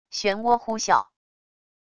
漩涡呼啸wav音频